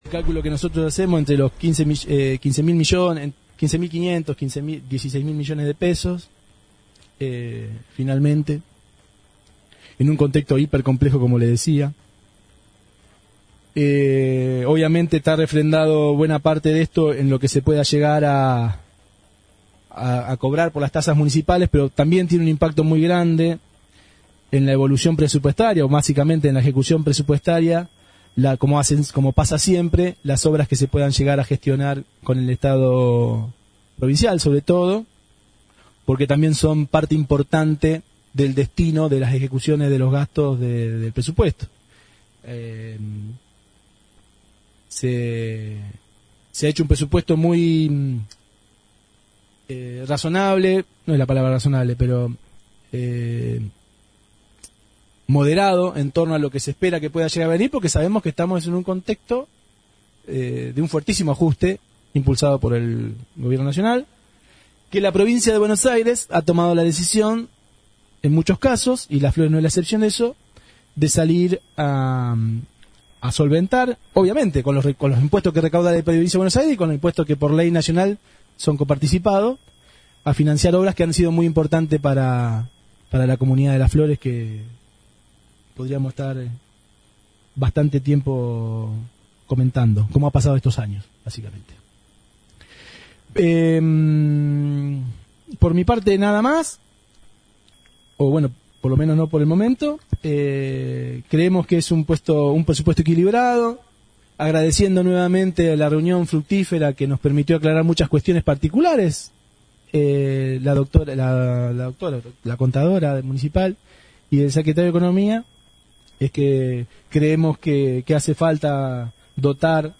Finalmente, se dio tratamiento y aprobación por mayoría al Presupuesto para el ejercicio del año próximo como así también se sancionó la Ordenanza Preparatoria Fiscal e Impositiva. El Presupuesto de Gastos y Cálculo de Recursos 2025 asciende a poco más de 24 mil millones de pesos mientras que la Ordenanza Fiscal e Impositiva contempla una suba del 45 al 80% en las tasas municipales. Aquí la palabra de los principales referentes de cada bloque político.